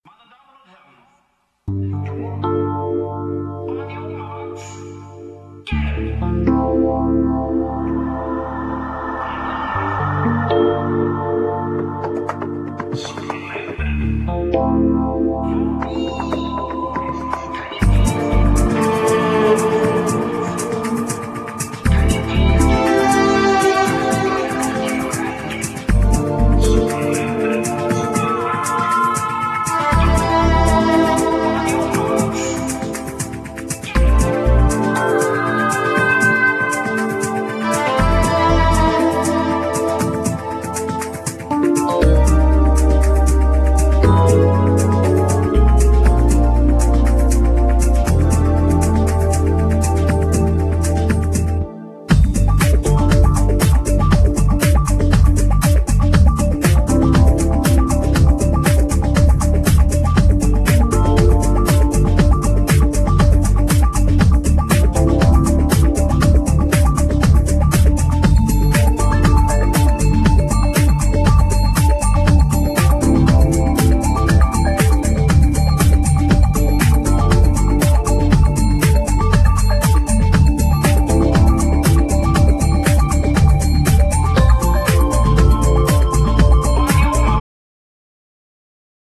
Genere : Electro - Pop